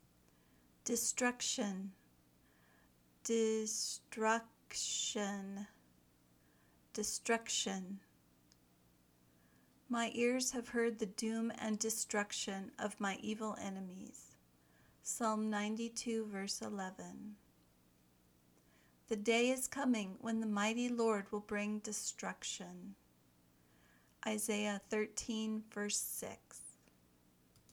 dɪ  ˈstrʌk  ʃən  (noun)